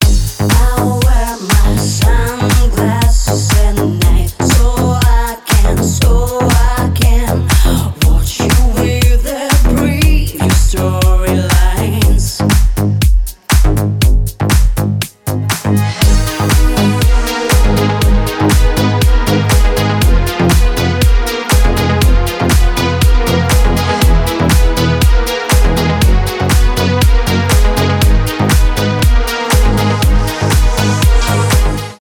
club house , deep house
retromix